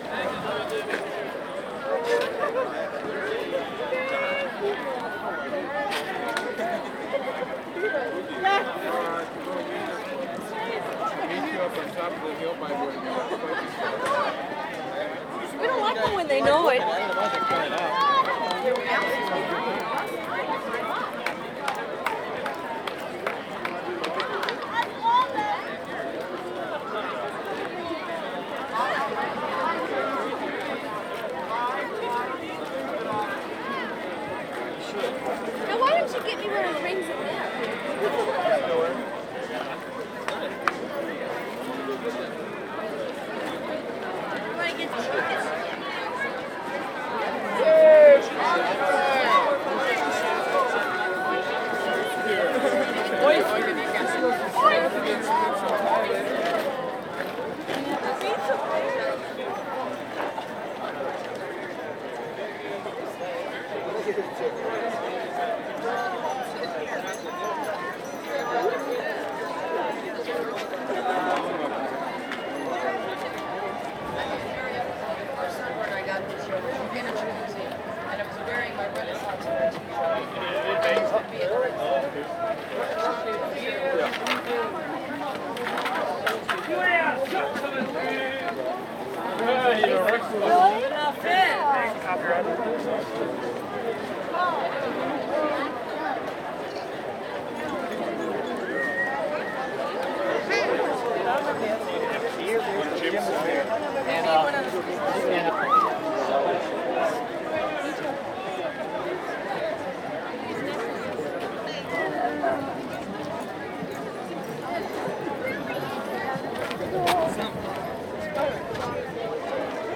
marketLoop.ogg